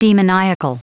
Prononciation du mot demoniacal en anglais (fichier audio)
Prononciation du mot : demoniacal